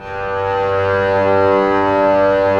Index of /90_sSampleCDs/Roland L-CD702/VOL-1/STR_Cbs FX/STR_Cbs Sul Pont